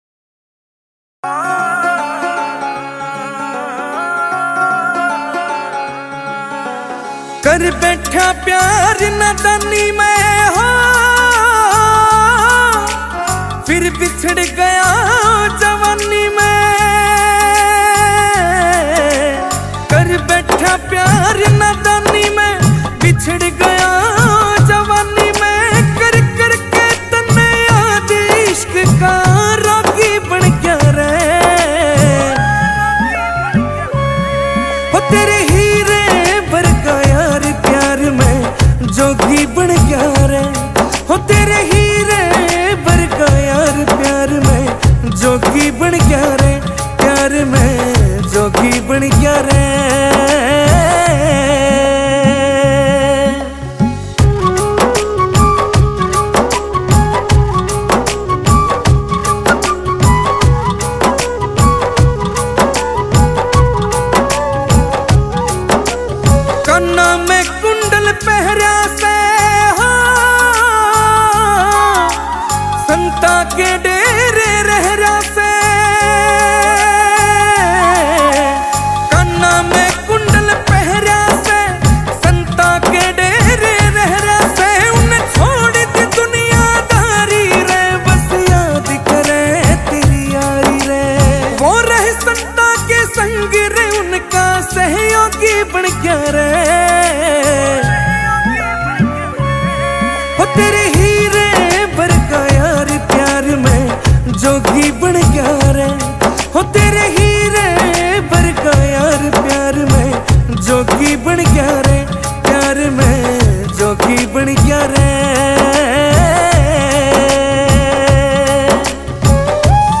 CategoryHaryanvi Mp3 Songs 2025